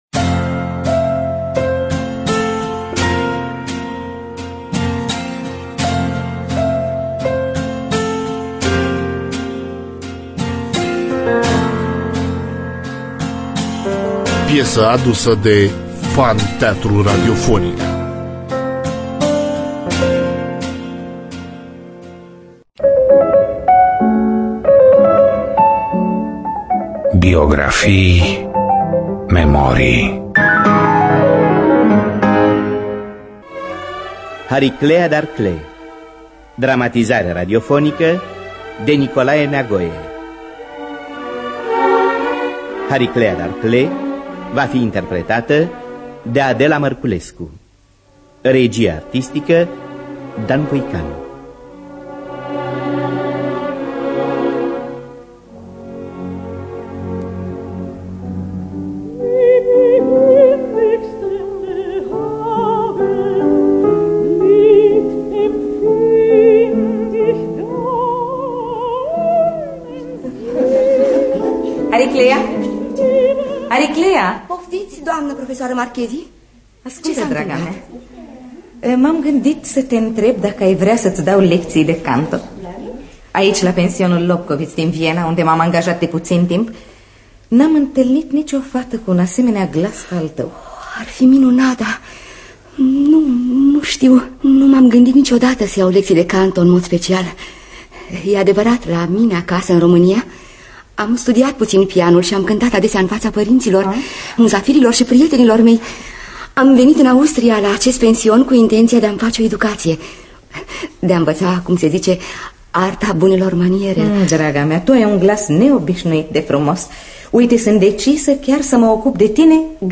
Biografii, memorii: Hariclea Darclée Scenariu radiofonic de Nicolae Neagoe.